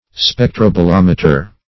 Search Result for " spectrobolometer" : The Collaborative International Dictionary of English v.0.48: Spectrobolometer \Spec`tro*bo*lom"e*ter\, n. (Physics) A combination of spectroscope and bolometer for determining the distribution of energy in a spectrum.